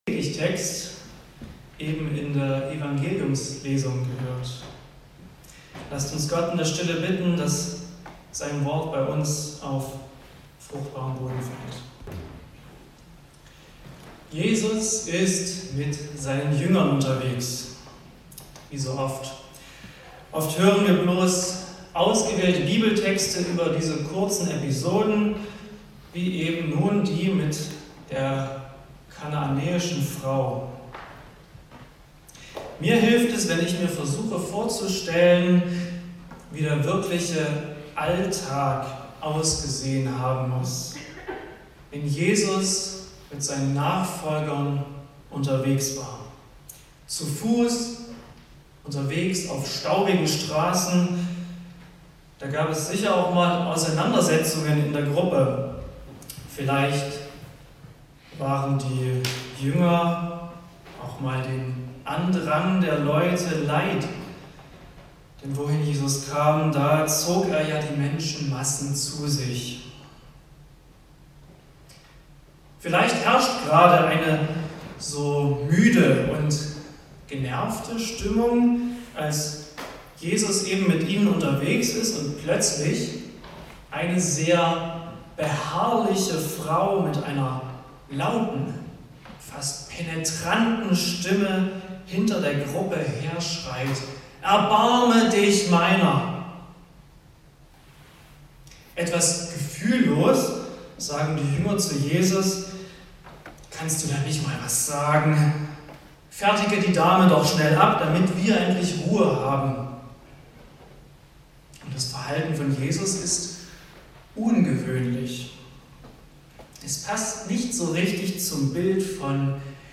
Predigtgottesdienst